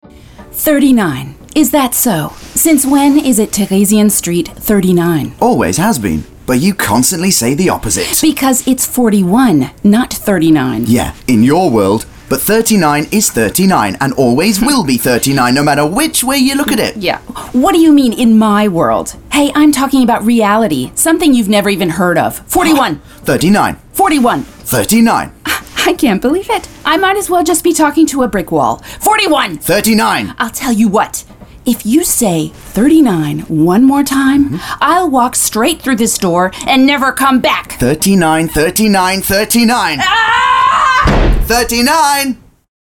American Voice-Over Artist Native Sprecherin US Englisch
Sprechprobe: Sonstiges (Muttersprache):